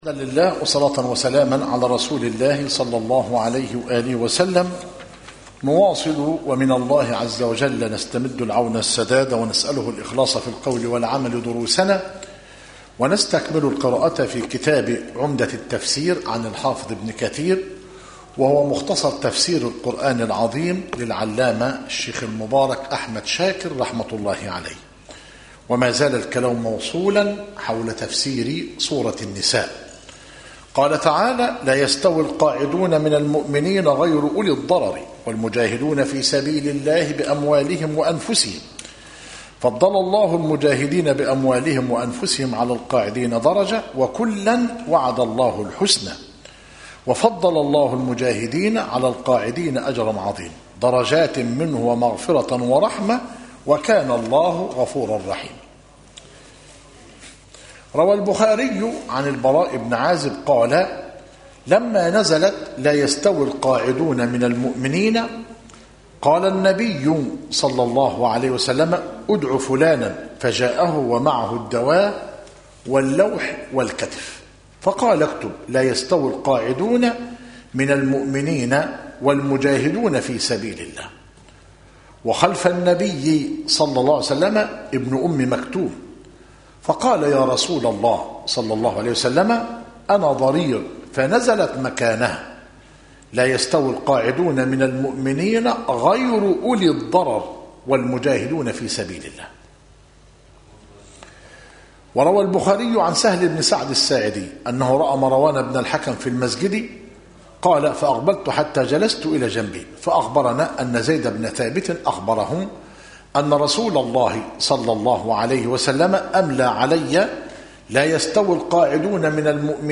عمدة التفسير مختصر تفسير ابن كثير للعلامة أحمد شاكر رحمه الله - مسجد التوحيد - ميت الرخا - زفتى - غربية - المحاضرة الحادية والثمانون - بتاريخ 26 - صفر- 1438هـ الموافق 26 - نوفمبر- 2016 م